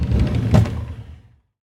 drawer.R.wav